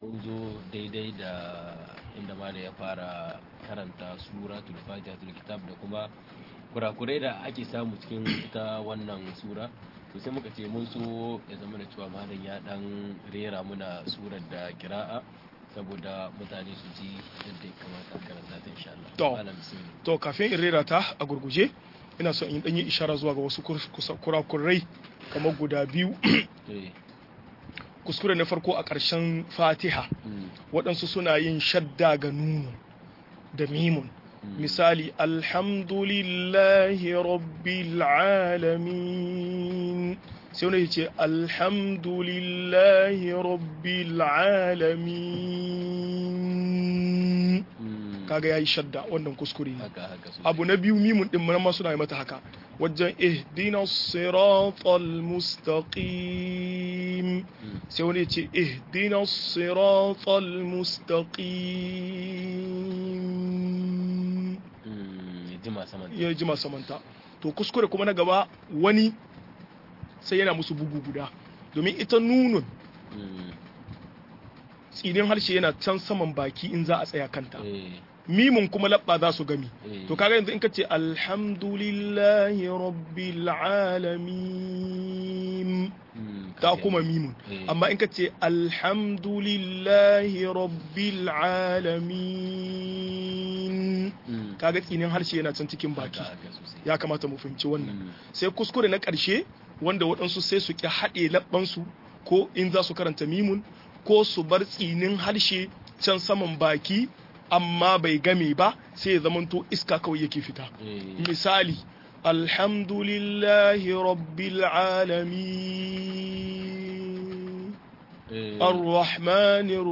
Bayani kan tajwidi - MUHADARA